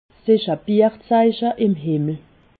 Haut Rhin
Ville Prononciation 68
Ribeauvillé